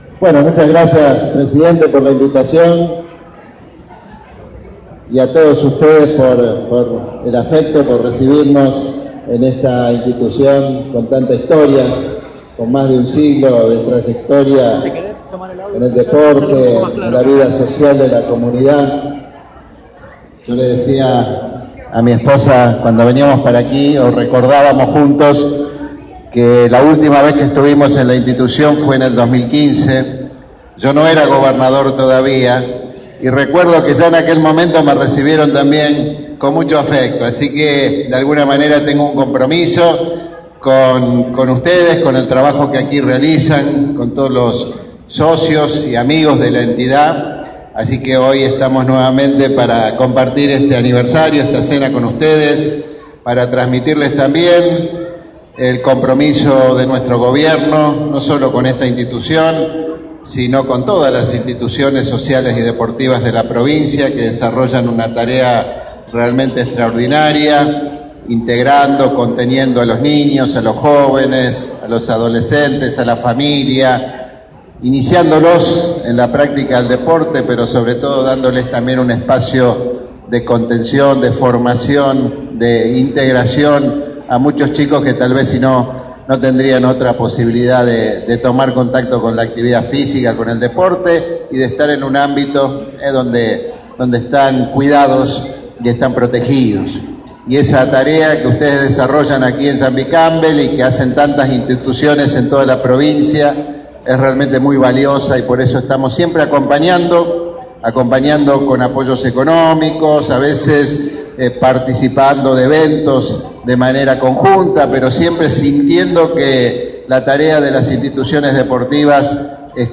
El gobernador Miguel Lifschitz participó este sábado de la celebración del 104° aniversario del Club San Martín de Llambi Campbell (departamento La Capital).